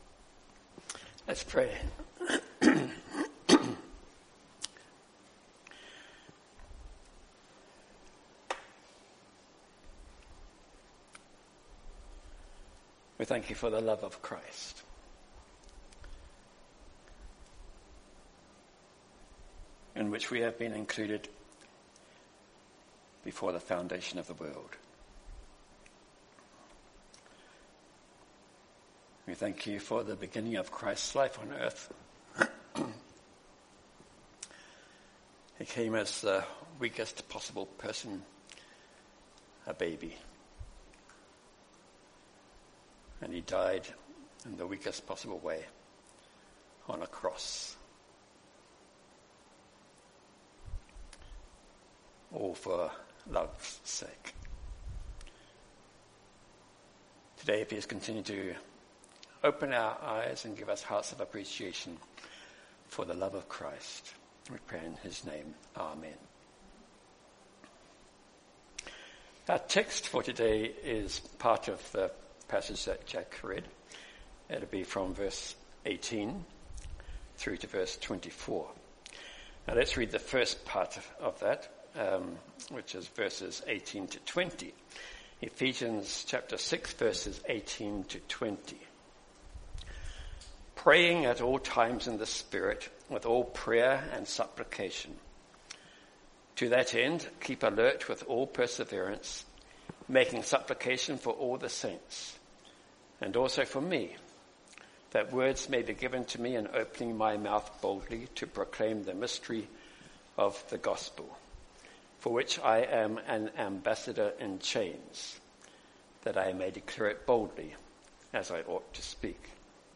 Download mp3 Previous Sermon of This Series End of series.